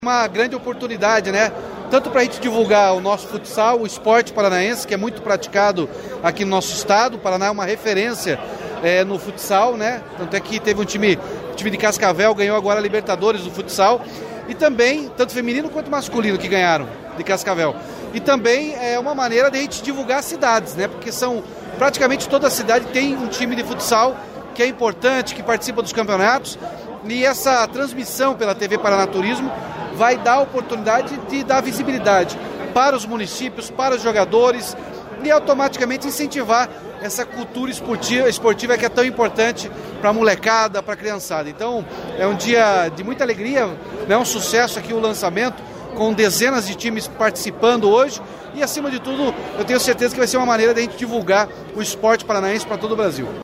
Sonora do governador Ratinho Junior sobre a transmissão do Campeonato Paranaense de Futsal pela TV Paraná Turismo